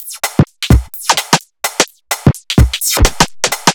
Index of /musicradar/uk-garage-samples/128bpm Lines n Loops/Beats
GA_BeatFiltC128-03.wav